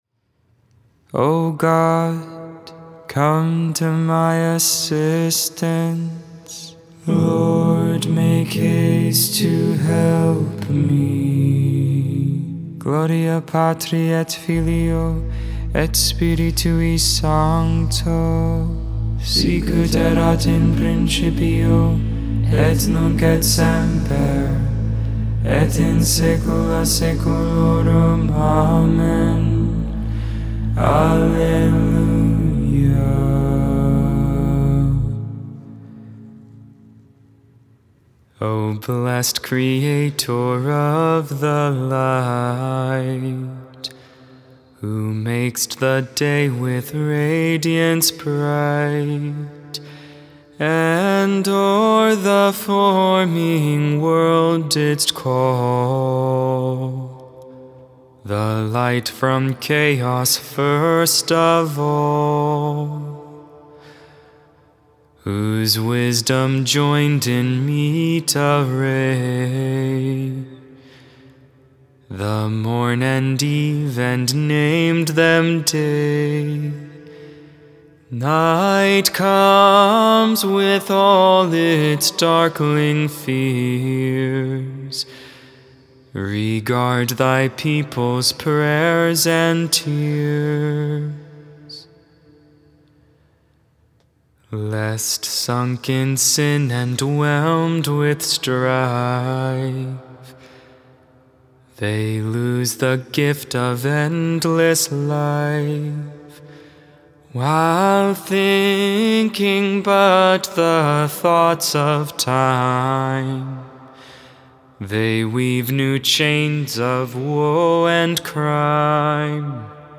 10.30.22 Vespers, Sunday Evening Prayer